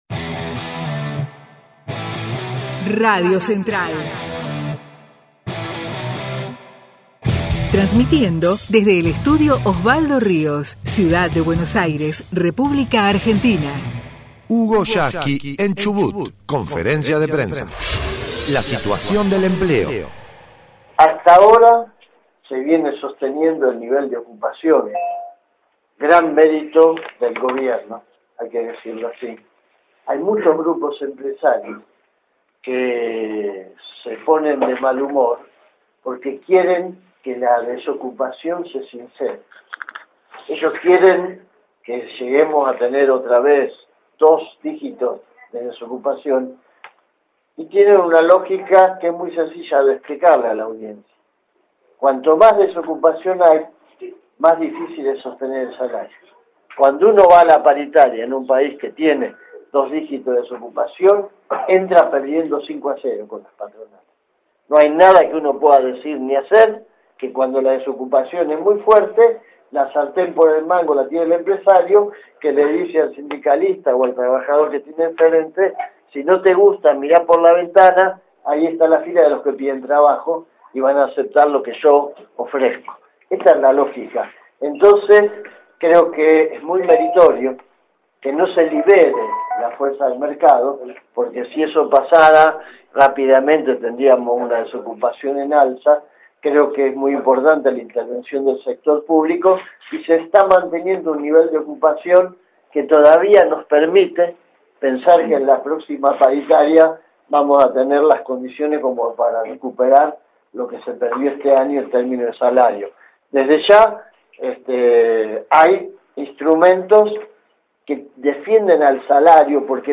HUGO YASKY en CHUBUT - conferencia de prensa - 5ª parte: LA SITUACIÓN DEL EMPLEO
El secretario General de la Central de Trabajadores de la Argentina (CTA) en la provincia patagónica.